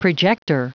Prononciation du mot projector en anglais (fichier audio)
Prononciation du mot : projector